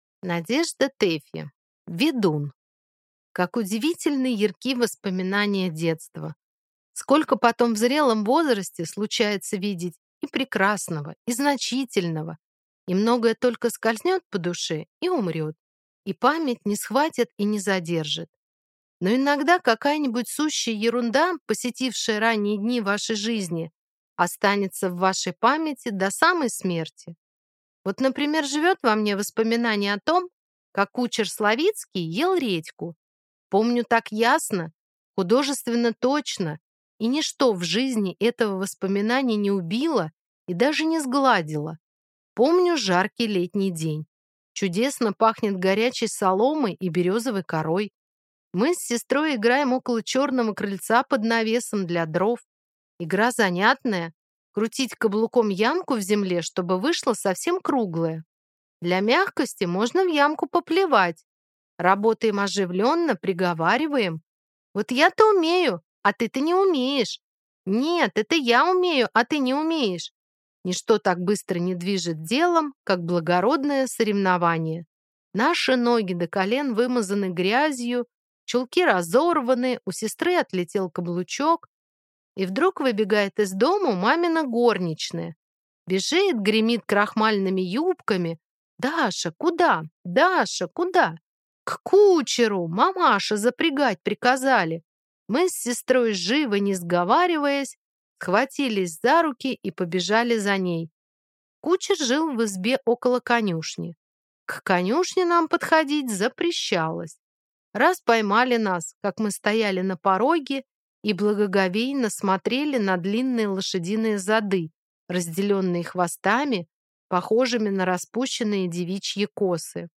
Аудиокнига Ведун | Библиотека аудиокниг
Прослушать и бесплатно скачать фрагмент аудиокниги